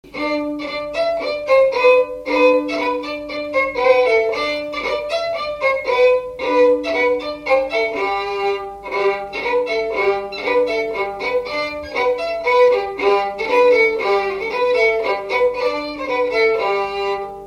Résumé instrumental
danse-jeu : guimbarde
Pièce musicale inédite